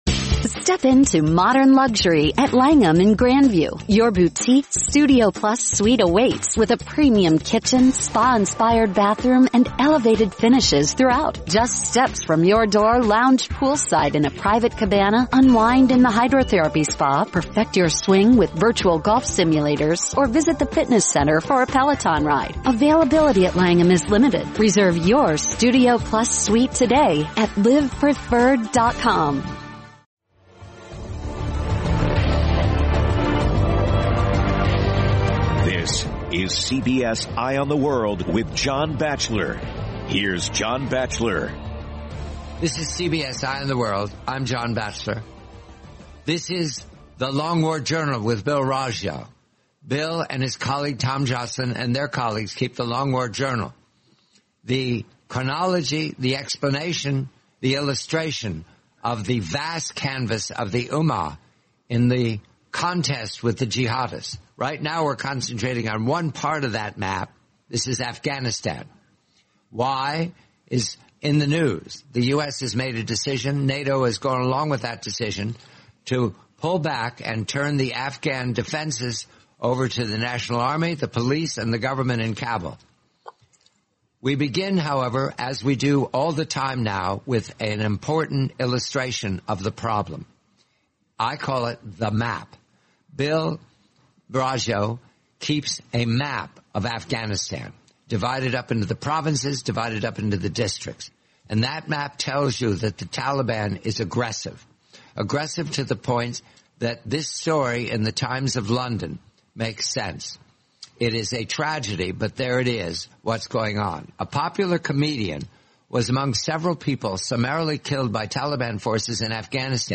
The complete, forty-minute interview